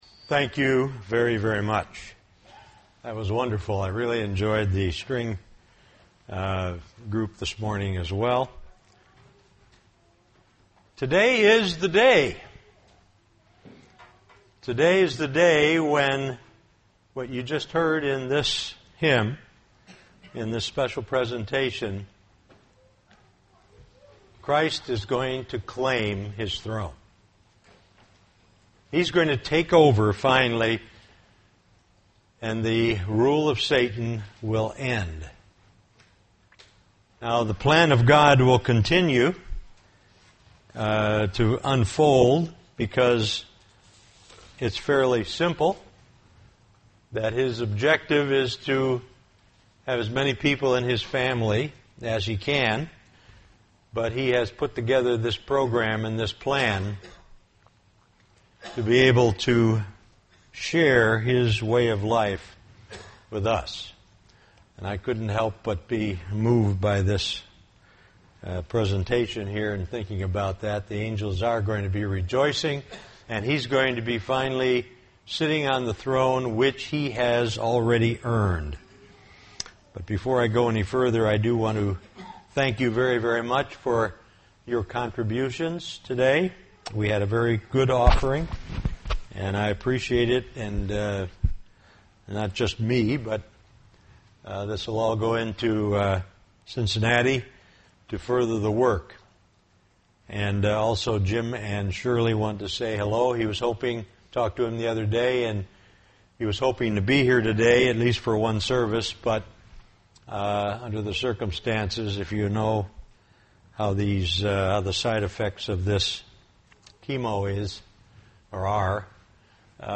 A rehearsal of blowing of Trumpets.
Given in Beloit, WI
UCG Sermon Studying the bible?